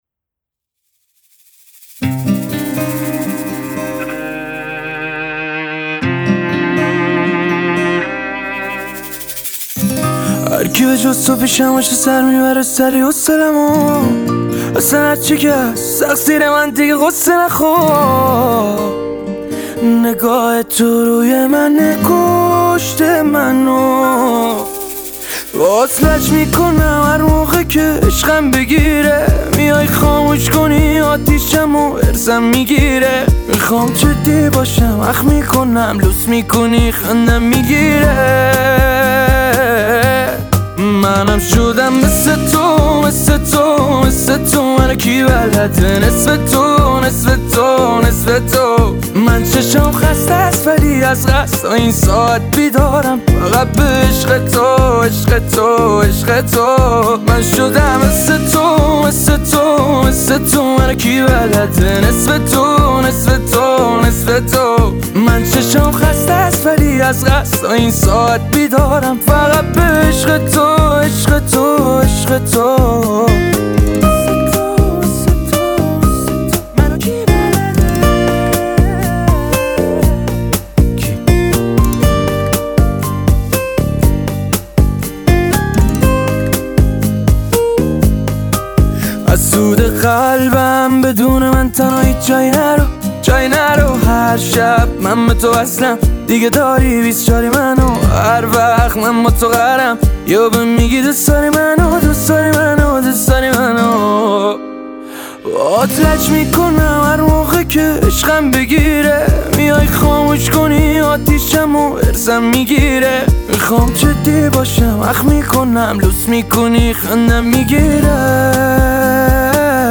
آهنگ احساسی